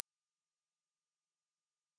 Descarga de Sonidos mp3 Gratis: ultrasonido 1.
ultrasonido-ultrasonico-1-.mp3